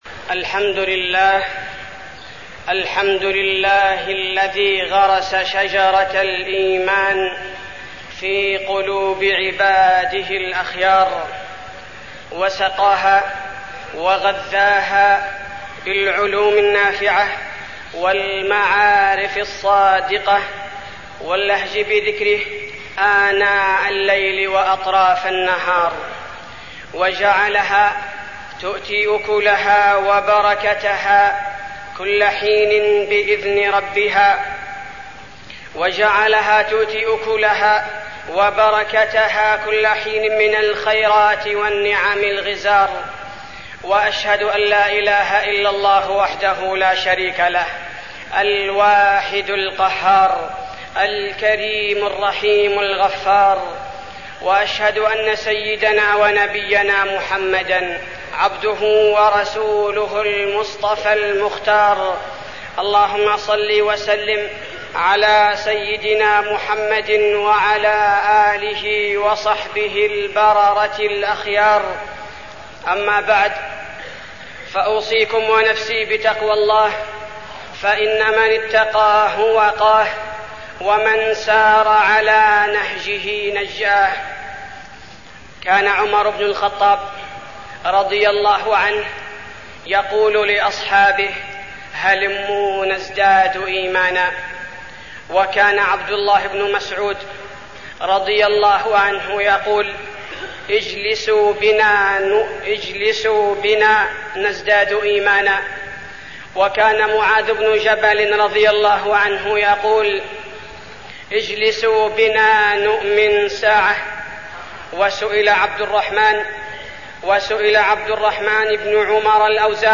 تاريخ النشر ١٥ جمادى الآخرة ١٤١٥ هـ المكان: المسجد النبوي الشيخ: فضيلة الشيخ عبدالباري الثبيتي فضيلة الشيخ عبدالباري الثبيتي الإيمان